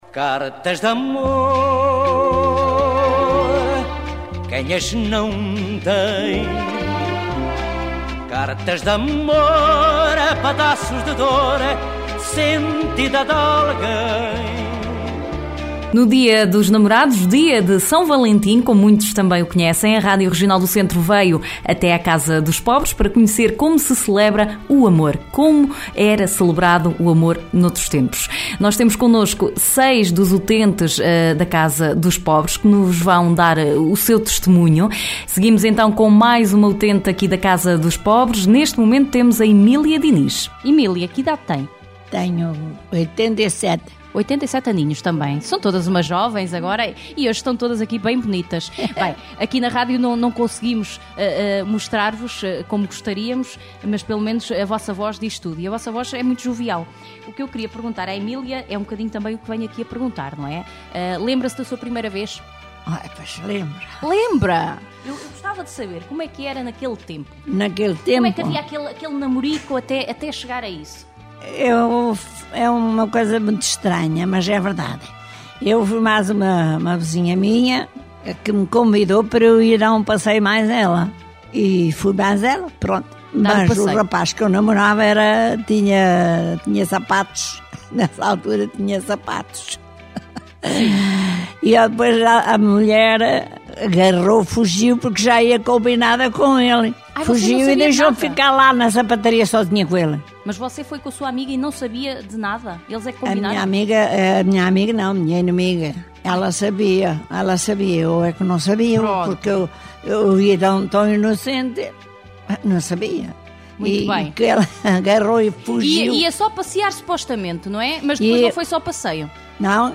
A Regional do Centro foi à Casa dos Pobres, em Coimbra, para saber junto de alguns utentes como se celebrava o amor há algumas décadas atrás. Oiça aqui um dos testemunhos.